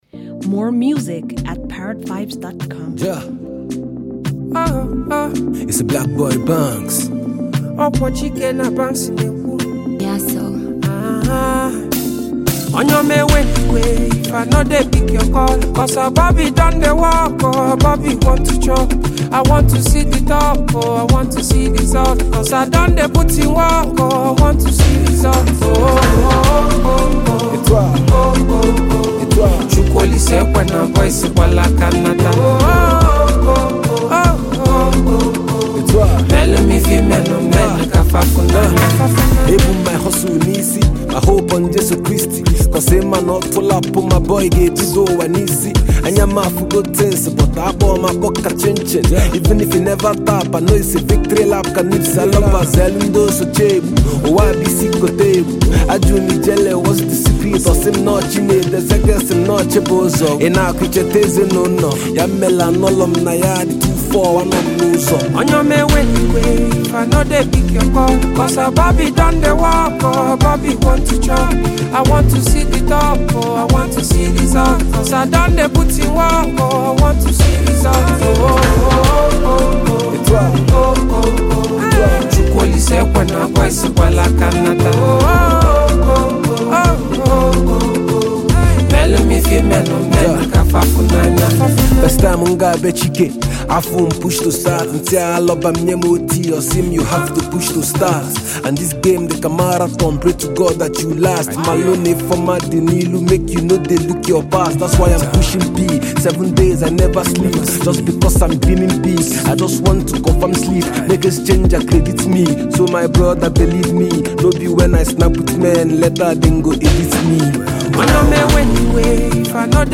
sweet-sounding new song